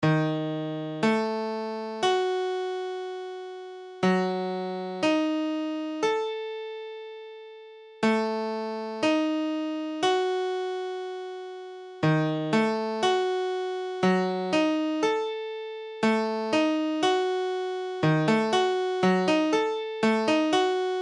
Tablature Ebdim.abcEbdim : accord de Mi bémol diminué
Mesure : 4/4
Tempo : 1/4=60
A la guitare, on réalise souvent les accords de quatre notes en plaçant la tierce à l'octave.
Forme fondamentale : tonique quinte diminuée tierce mineure